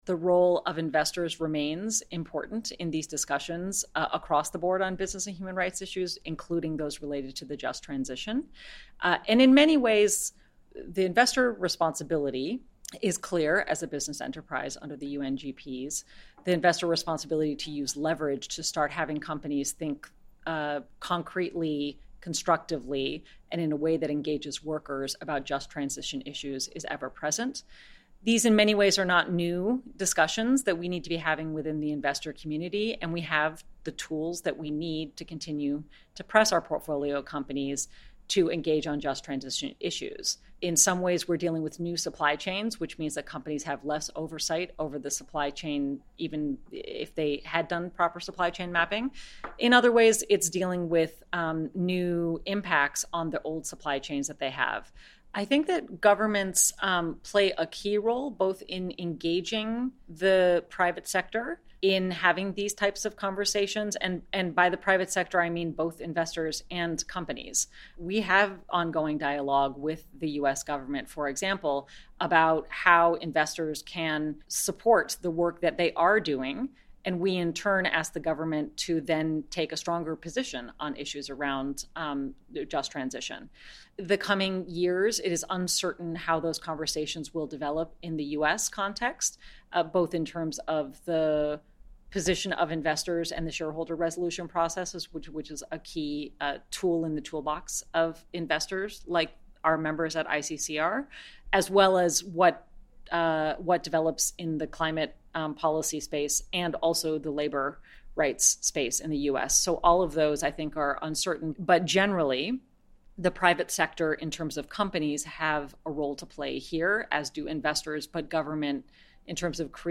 The role of investors and governments in the just transition - live from UN Business and Human Rights Forum